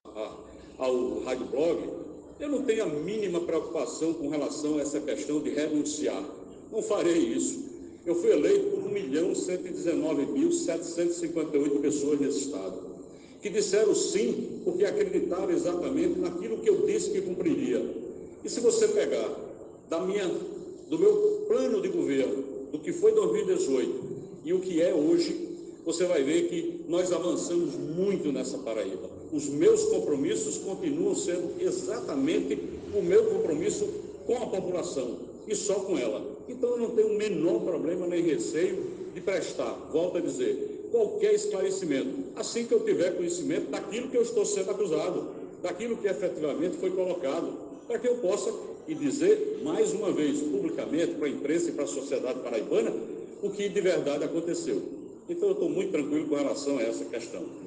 O Governador João Azevedo  negou que tenha cogitado renunciar e foi enfático com os repórteres que cobrem sua entrevista coletiva.
Ouça a fala de João Azevedo: